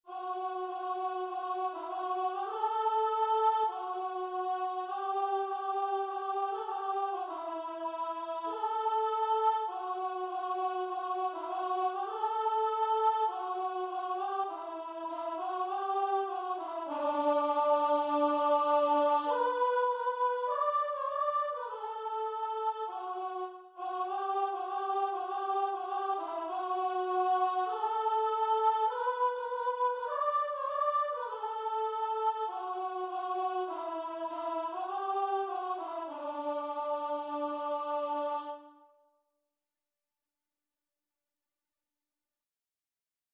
Christian
4/4 (View more 4/4 Music)